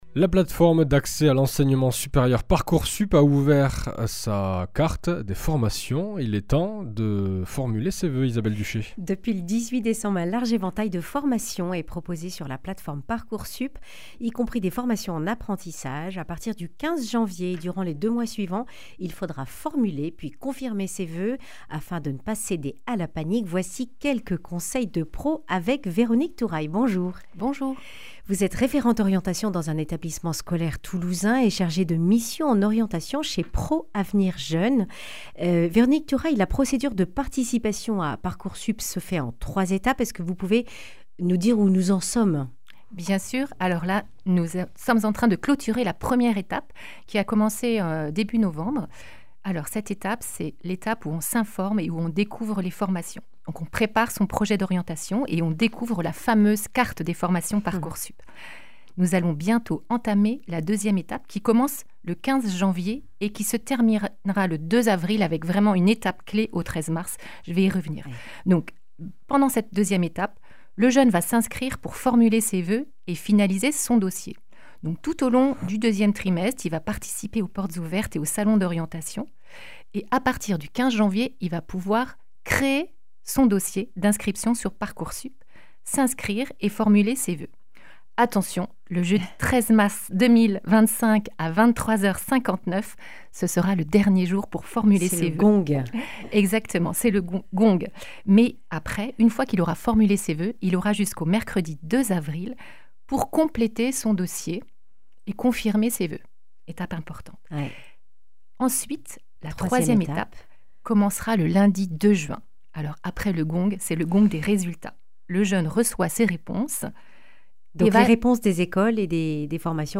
Accueil \ Emissions \ Information \ Régionale \ Le grand entretien \ ParcourSup : pas de panique !